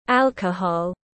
Rượu tiếng anh gọi là alcohol, phiên âm tiếng anh đọc là /ˈæl.kə.hɒl/
Alcohol /ˈæl.kə.hɒl/